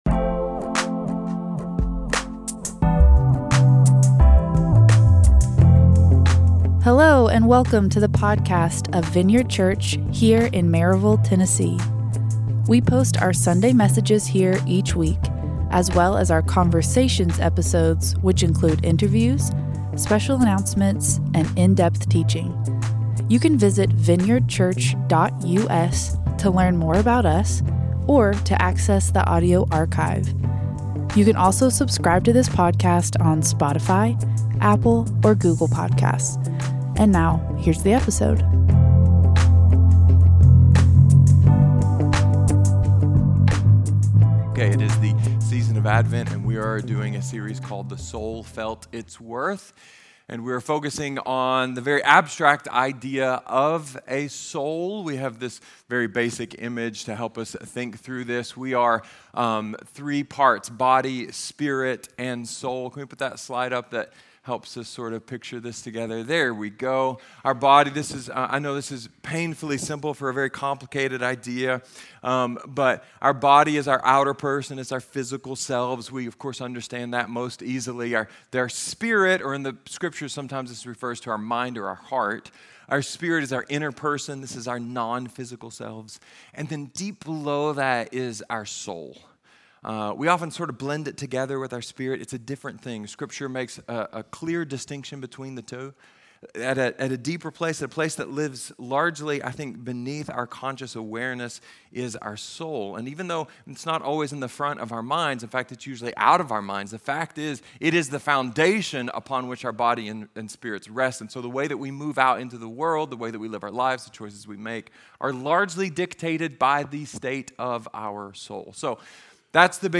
" The Soul Felt Its Worth " Our 2023 Advent sermon series.